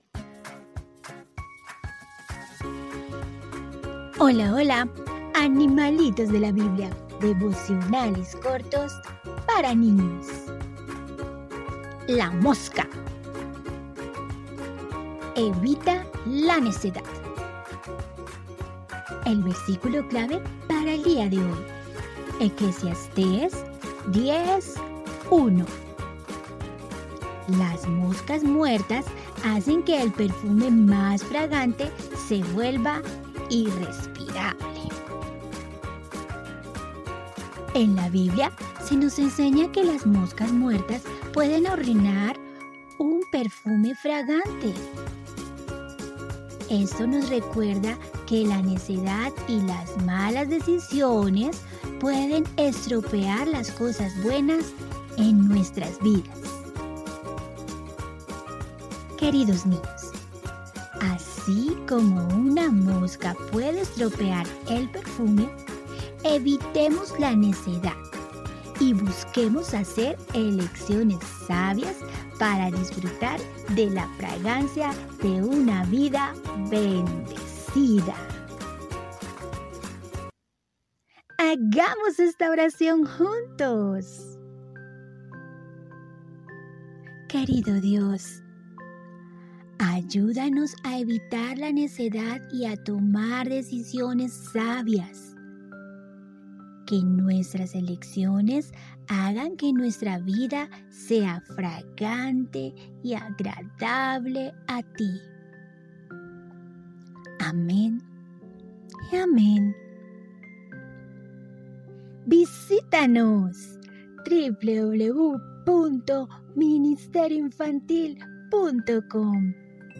Animalitos de la Biblia – Devocionales Cortos para Niños